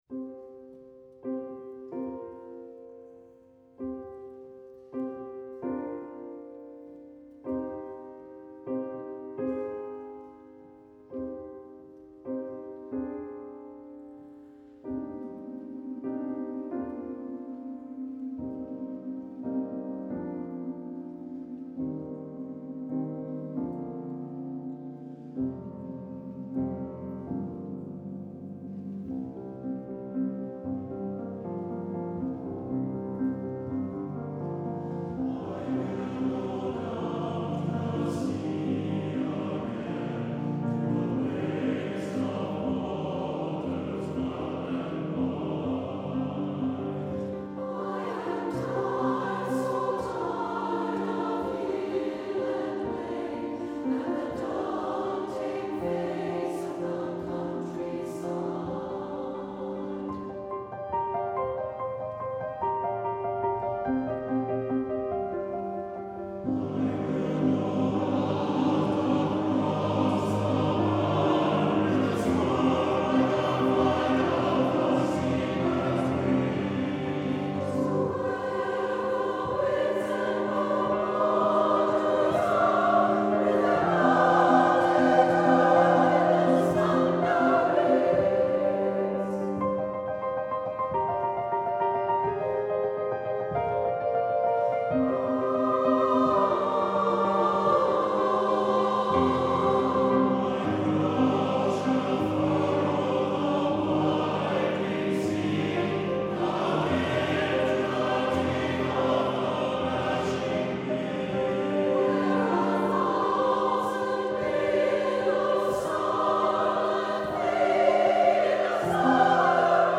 for SATB Chorus and Piano (2004)
This is sustained and powerful music, with the rolling waves often portrayed in the piano accompaniment.